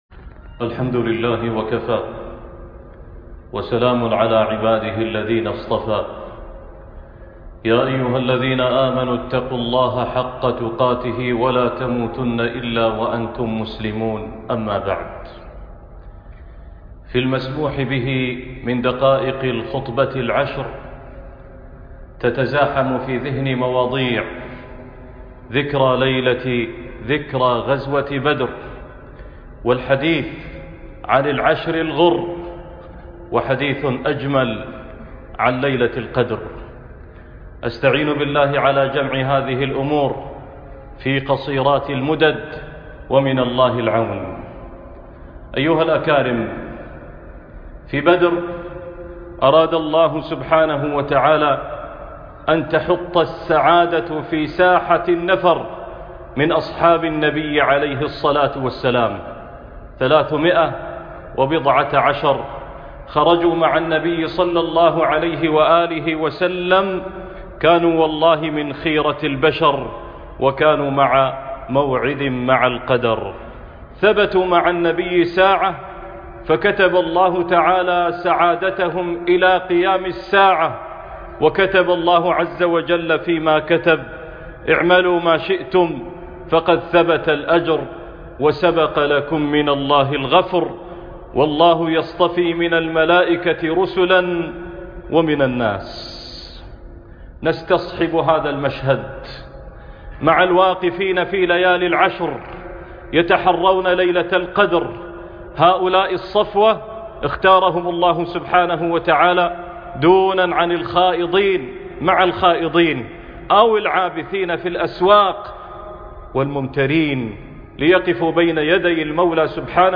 بدر وعشر - خطبة الجمعة